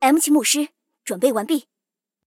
M7牧师编入语音.OGG